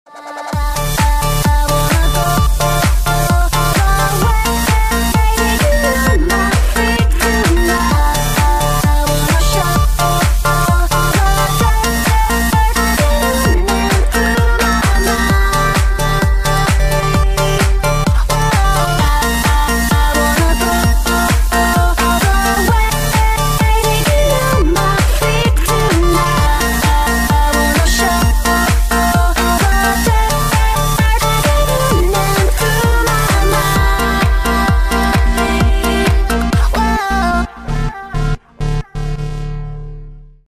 • Качество: 128, Stereo
громкие
женский вокал
dance
EDM
электронная музыка
club
electro house